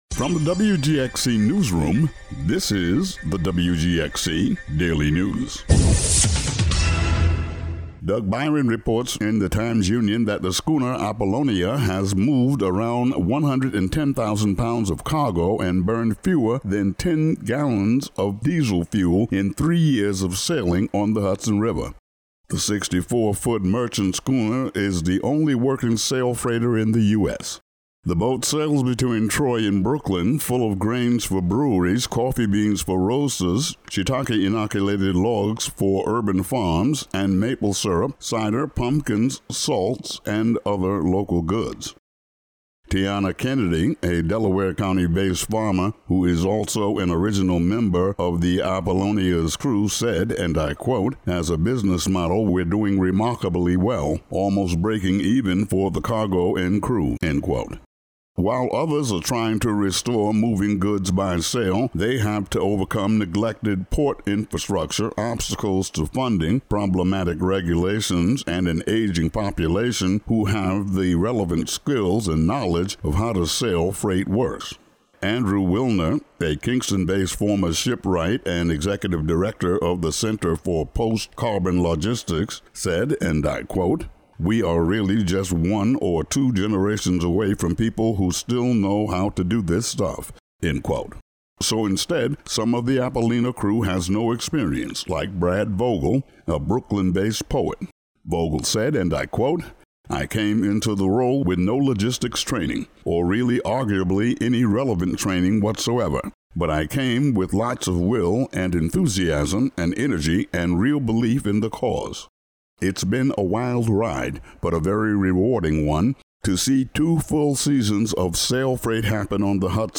Today's daily news audio update.